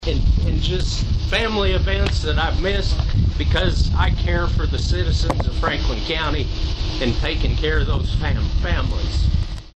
BENTON – State and county leaders, first responders, and community members showed up for Wednesday’s ribbon cutting ceremony and open house at the new Franklin County Coroner’s Office and Morgue in Benton.